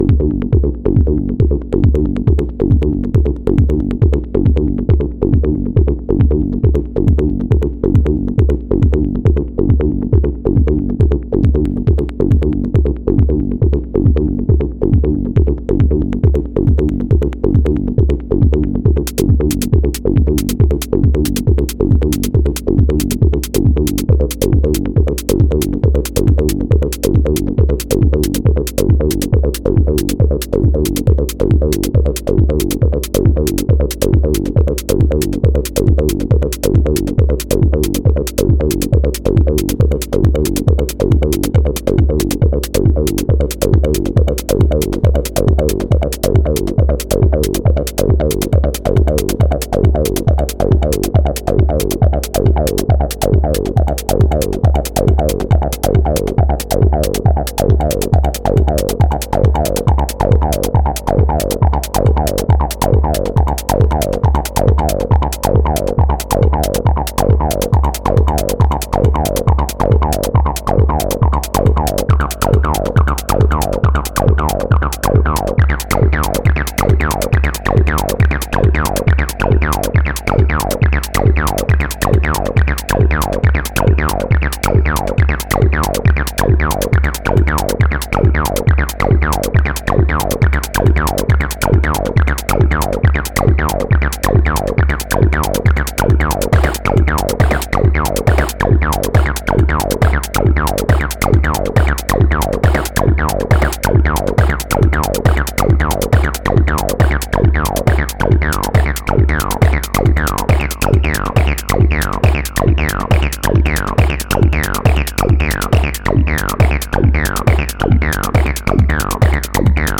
heres the acidlab with nothing past three quarters!!!
Sounds lovely :slight_smile: